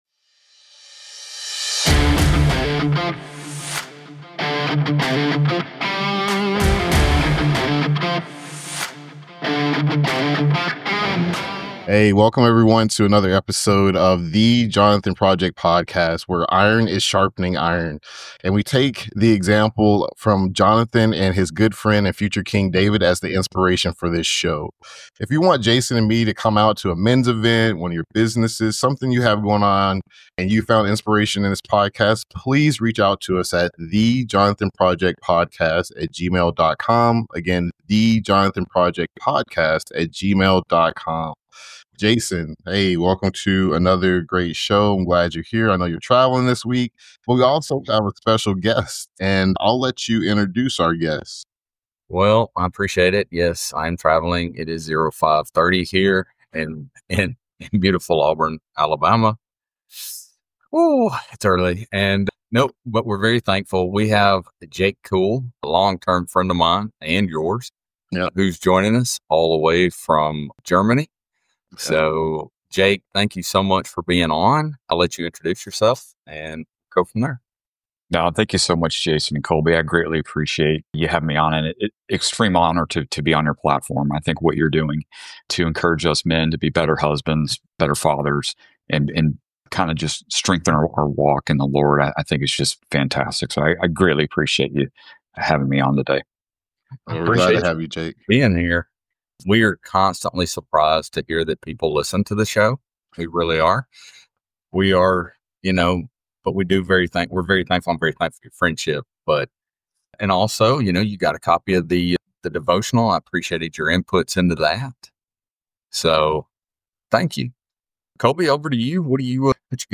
The good news is that the Bible has much to say about resilience. Join us this week with a special guest as we dive into an honest disc